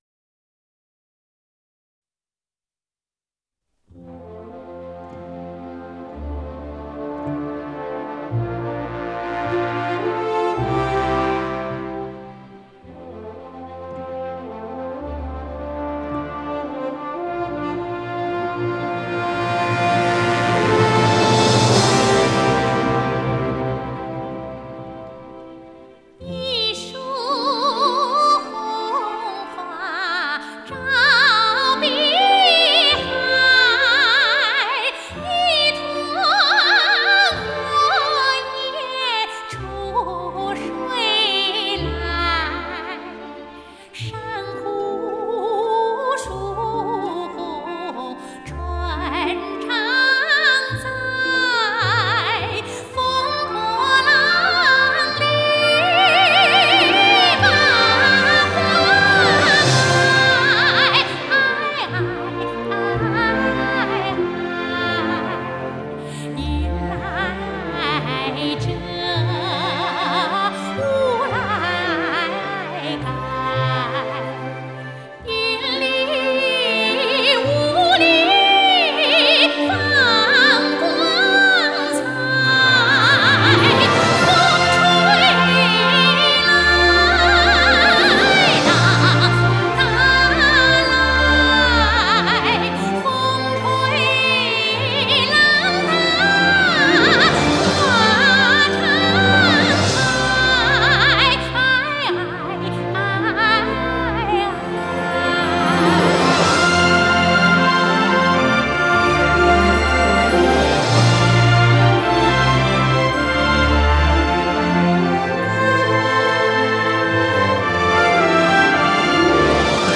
Жанр: Chinese pop ∕ Chinese folk